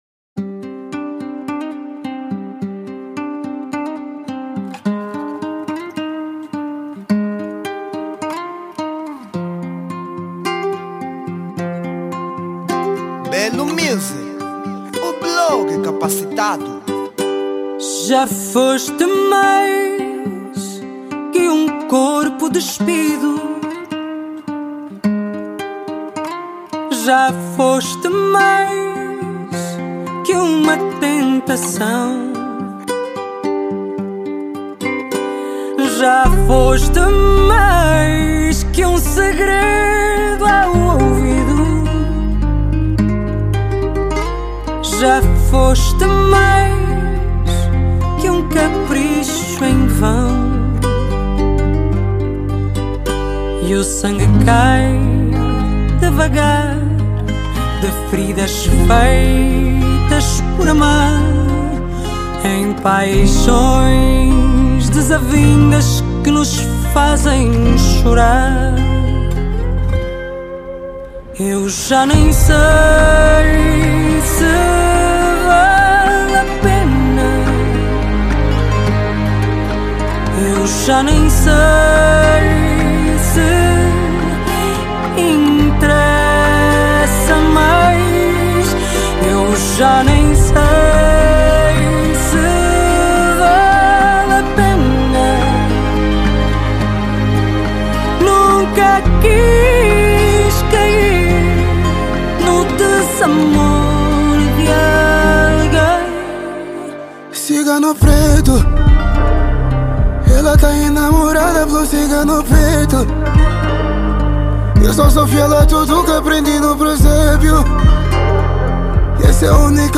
Género : RnB